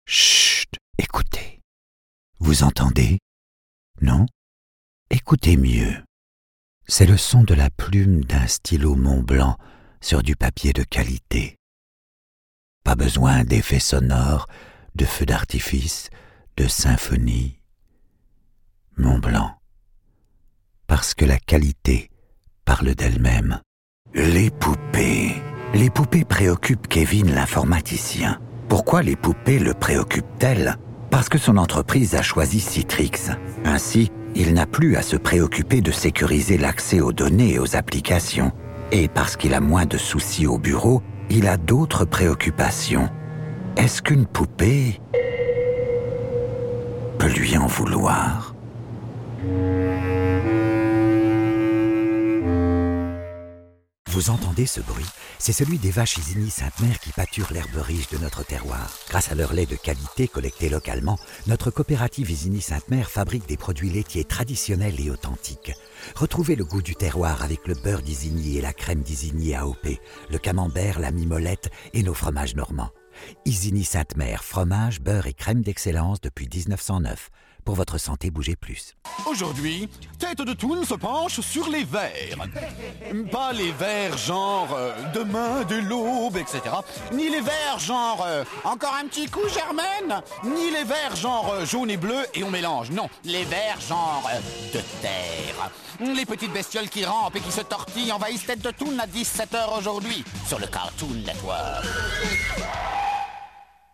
Commercial
French Language Showreel
Male
Gravelly
Gravitas
Relaxed